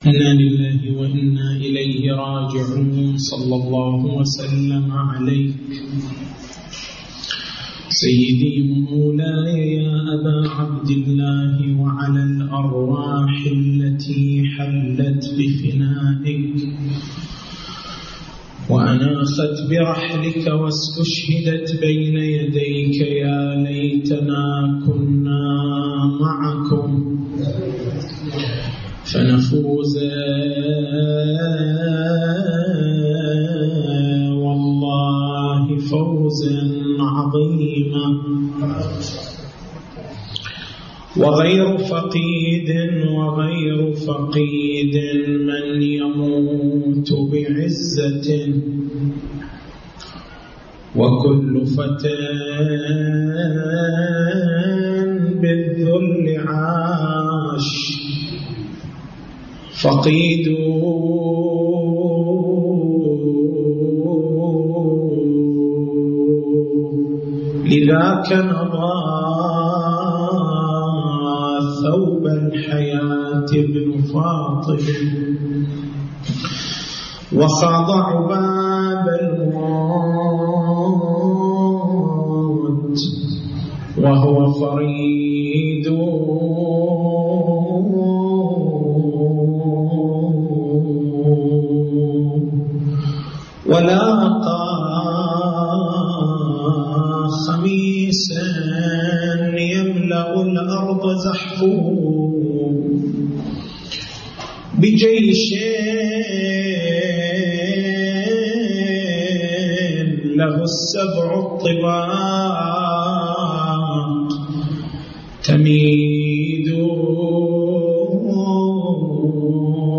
تاريخ المحاضرة: 29/09/1431 نقاط البحث: ما هو مبرّر الشيعة للاعتقاد بالبداء؟